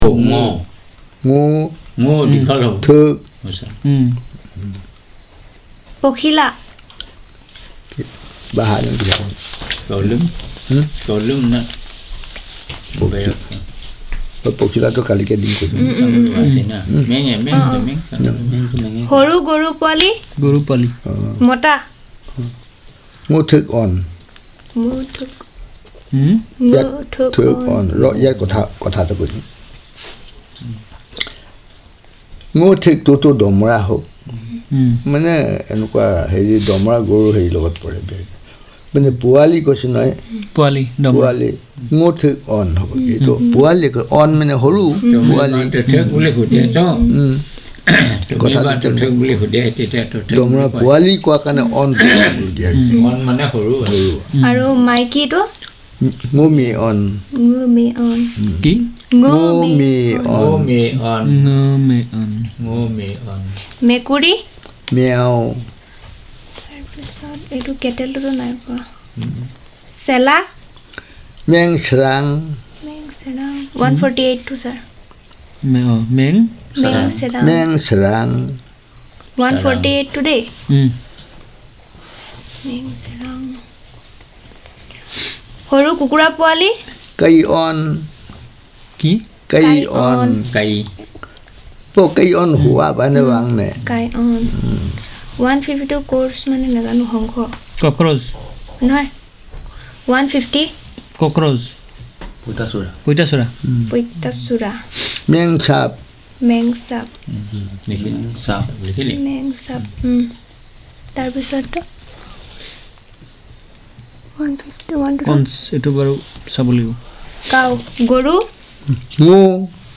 NotesThis is an elicitation of words about animals, insects, flora, and fauna using the questionnaire method.